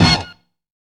WAH HORNS.wav